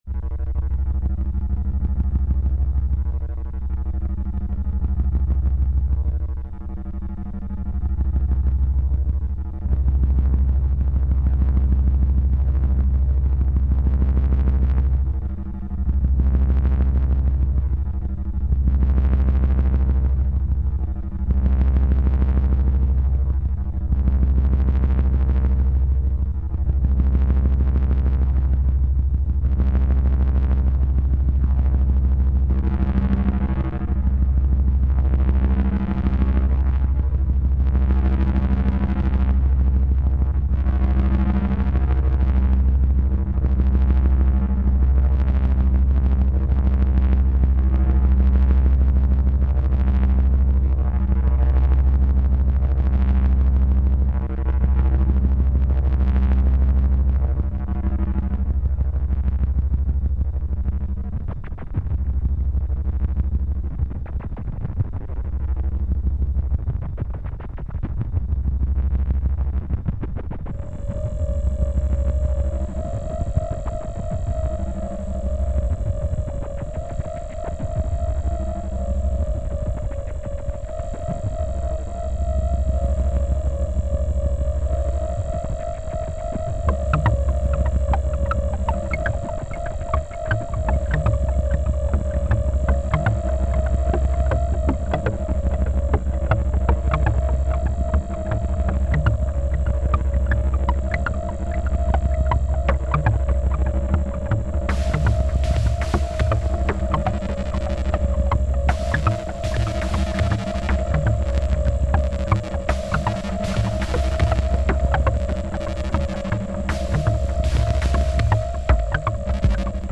File under: Strange Electronica / Techno